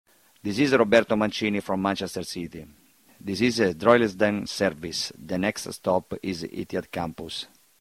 Roberto Mancini tram announcement
Manchester City manager Roberto Manchester announces that the next stop on the Metrolink tram is the Etihad Campus